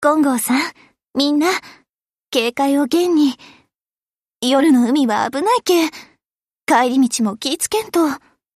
She speaks in a Hiroshima dialect.